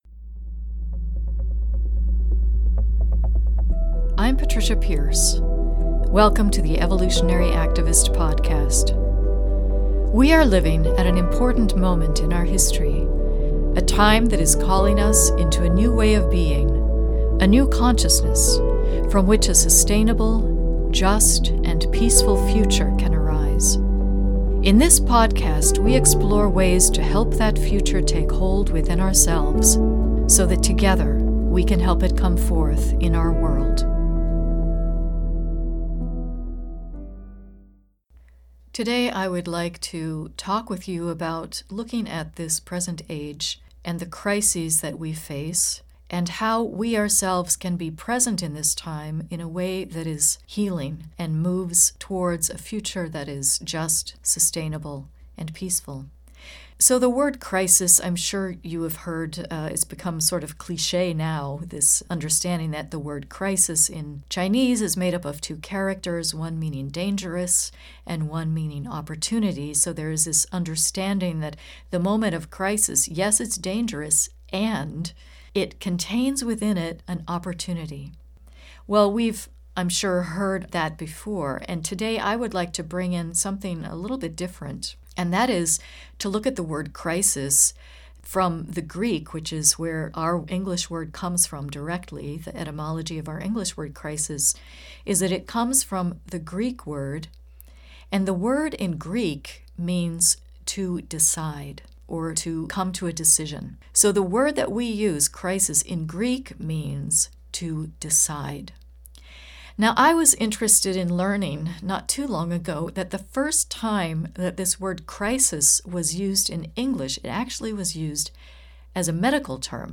Soundtrack music